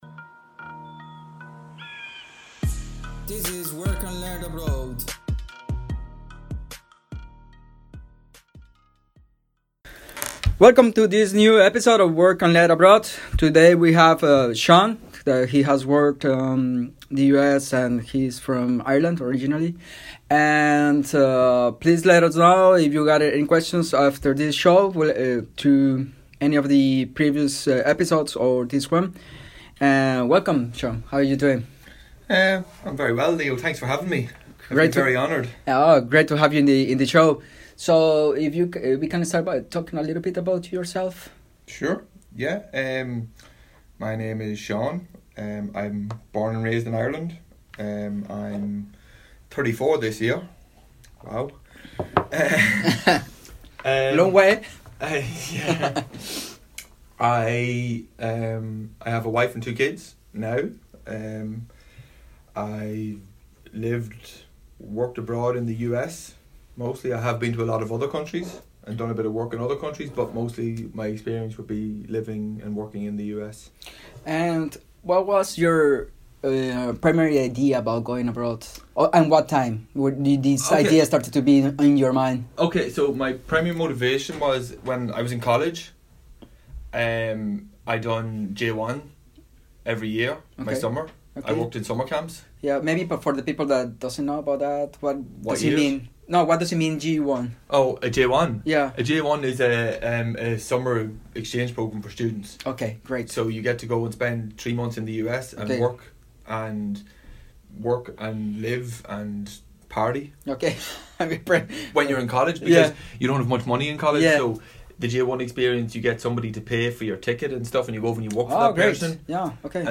37 Work Abroad Interview